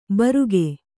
♪ baruge